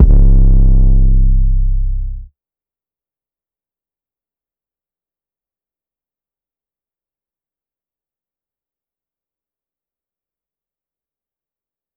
808 (CAROUSEL C).wav